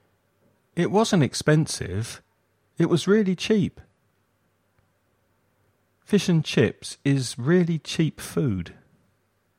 /i:/  - cheap          /I/ - chip